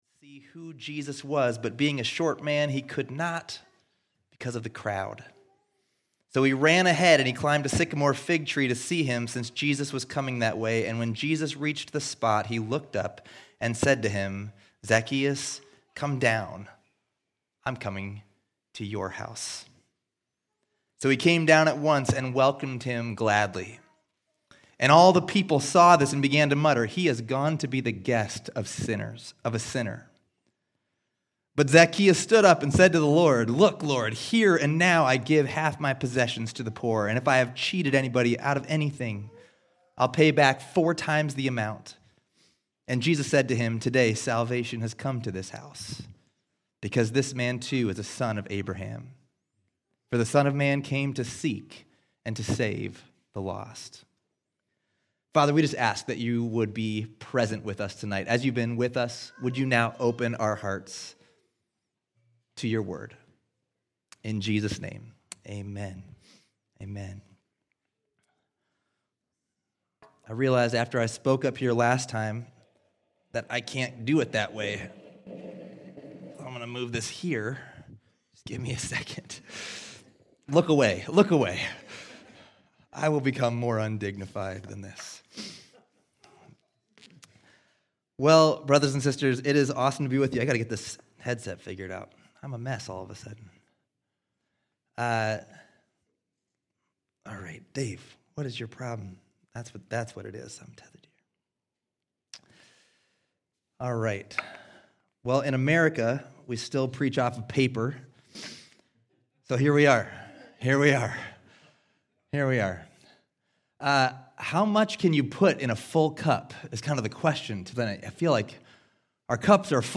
RESTORE Conference 2024
Current Sermon